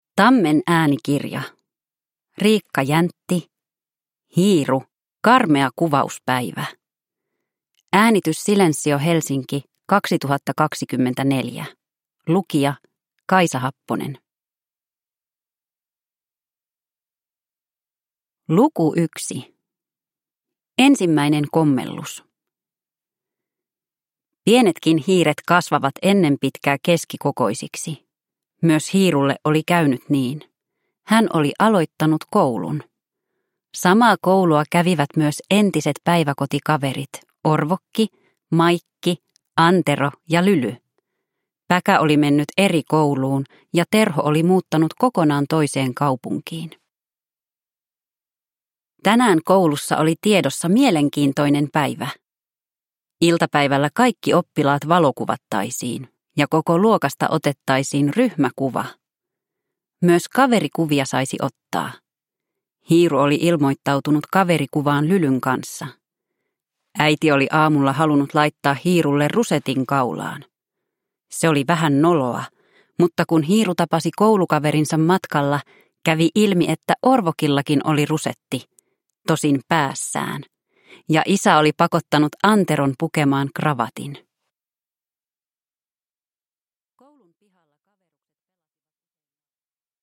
Hiiru. Karmea kuvauspäivä (ljudbok) av Riikka Jäntti